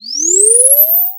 まずは、せっかくなのでジャンプの効果音をブラウザで動くシンセサイザーJFXRで作ってみましょう。
今度は「空を飛び始める」効果音として下記設定をして、[Export]ボタンからJump2.wavというファイル名DXライブラリのプロジェクトのSEフォルダに保存しましょう。
Jump2.wav